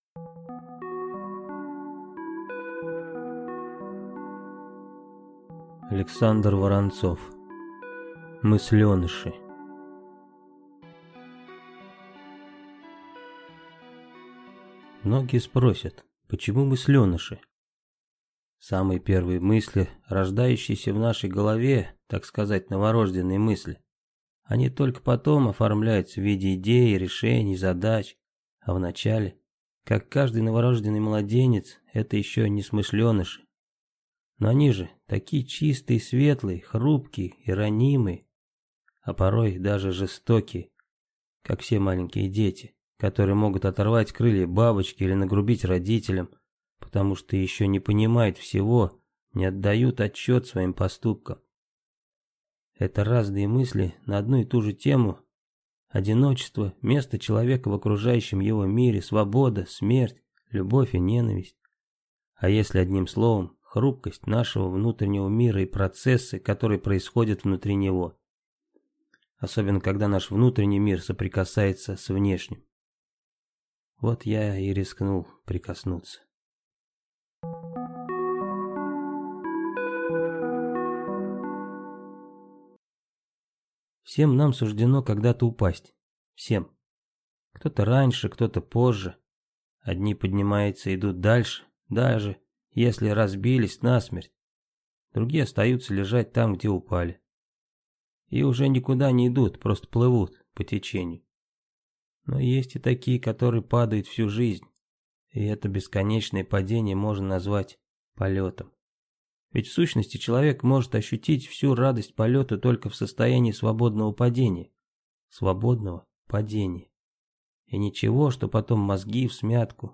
Aудиокнига МыслЁныши